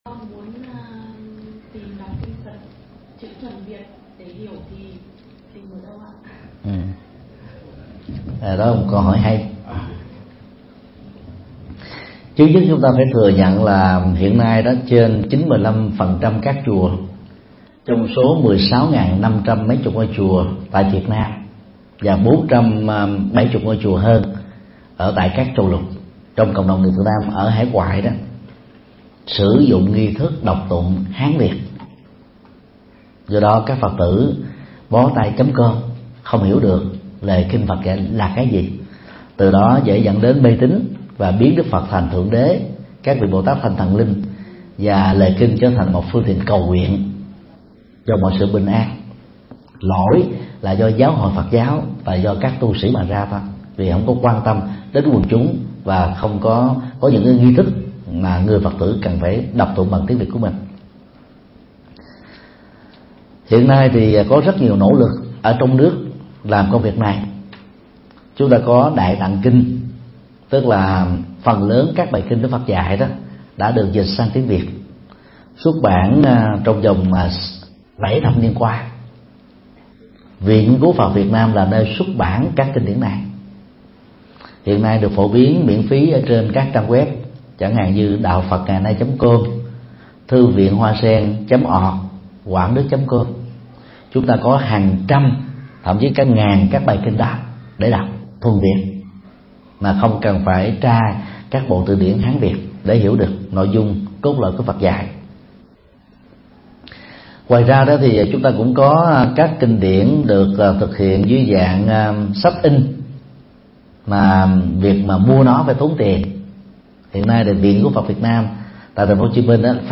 Vấn đáp: Kinh điển cho người tại gia dành cho người Việt – Thích Nhật Từ